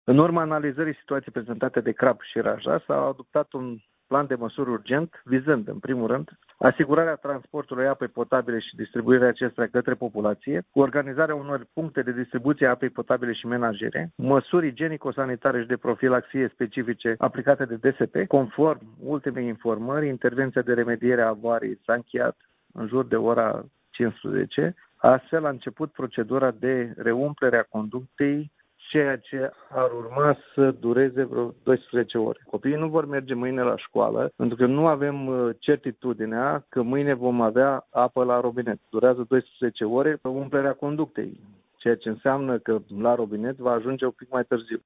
Prefectul Valentin Ivancea: